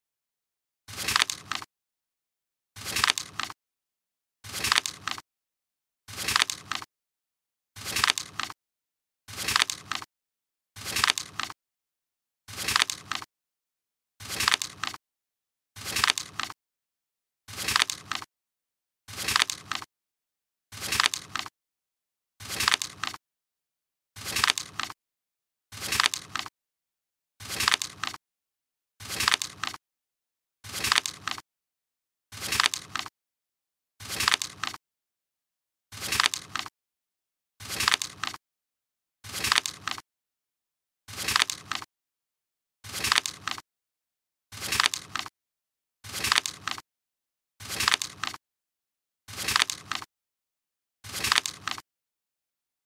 Calendar Days Counting Animation Green sound effects free download